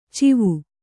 ♪ civu